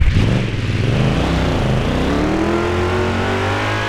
Index of /server/sound/vehicles/sgmcars/buggy